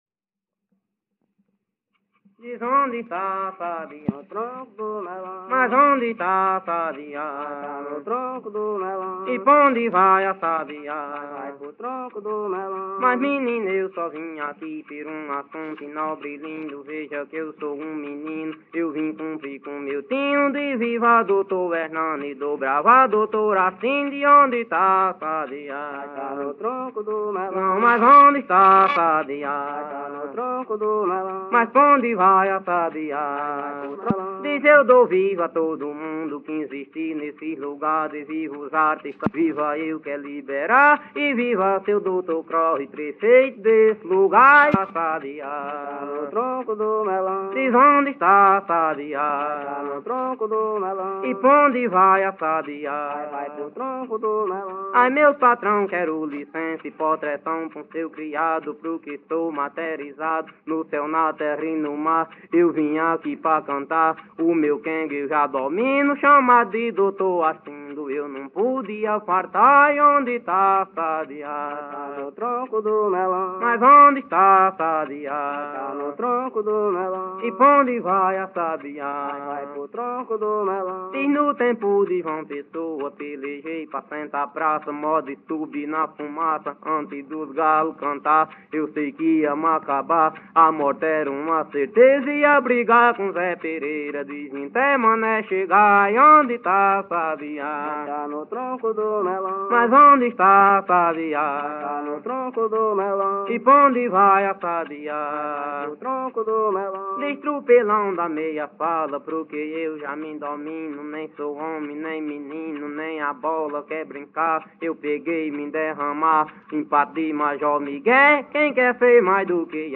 Coco embolada – “”Onde está o sabiá?””